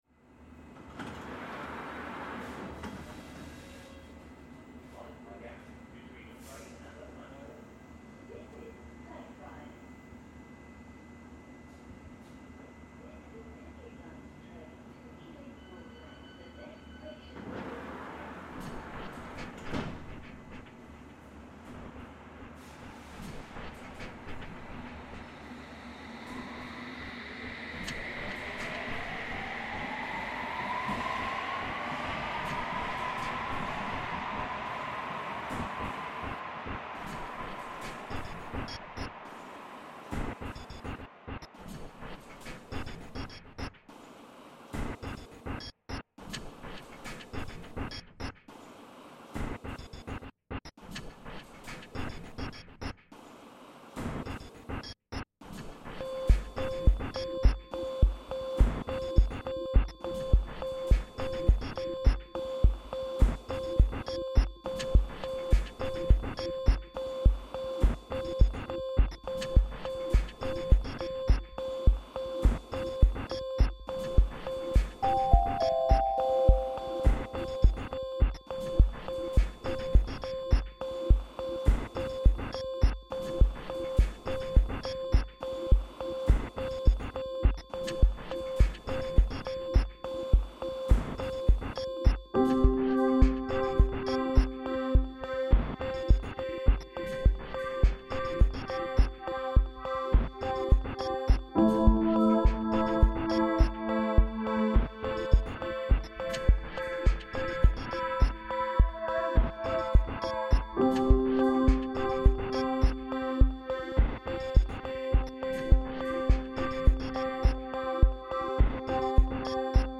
Blackfriars tube trains reimagined